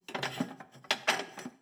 SFX_Plates_04.wav